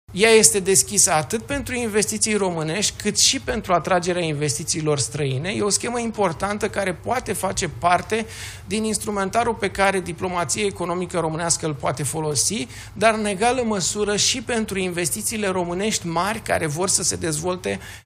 „Pachetul de relansare economică” a fost prezentat joi, 5 februarie, la Palatul Victoria.
Ministrul Finanțelor, Alexandru Nazare: „Este o schemă importantă care poate face parte din instrumentalul pe care diplomația economică românească îl poate folosi”